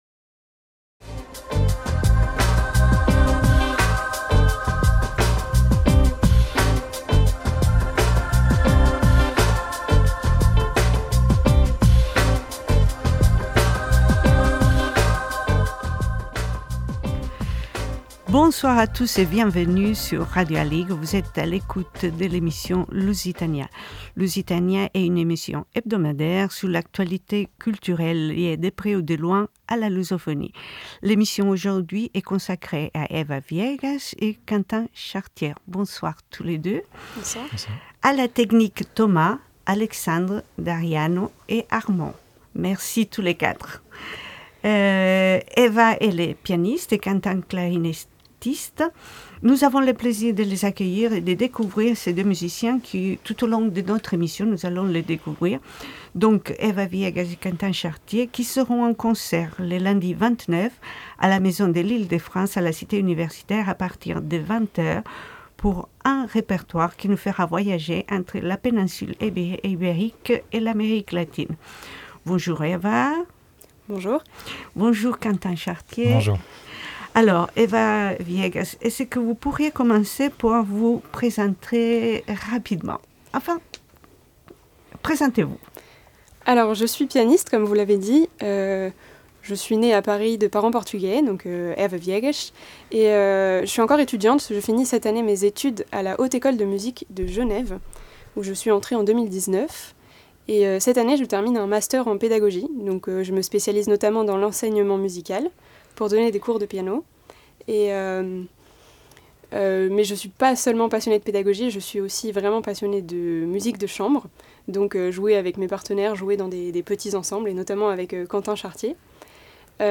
- W. A. Mozart : Concerto pour clarinette en la majeur interprète par Martin Fröst